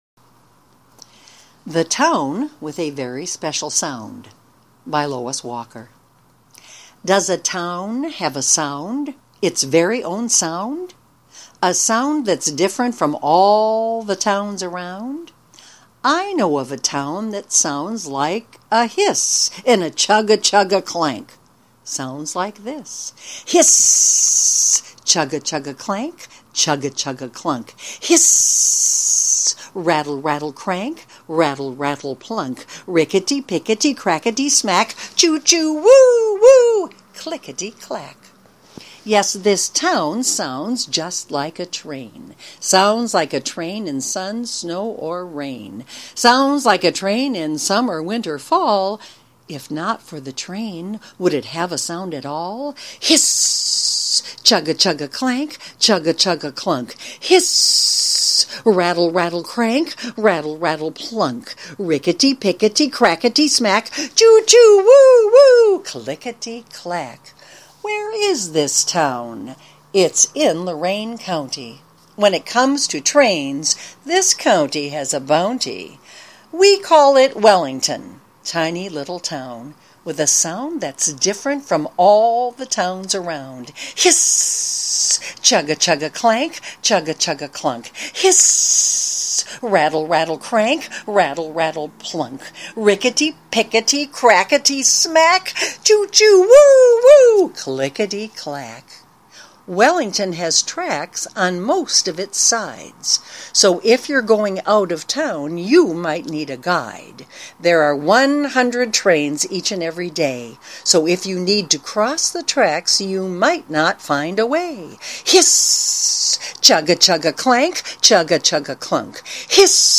Choral Reading Scripts
The chorus of this script asks the readers to duplicate many of the sounds that come from trains. To make the reading even more fun, the chorus can be read to the rhythm of a real train as it chug-a-lugs along the tracks.
Readers: ALL, ALL GIRLS, ALL BOYS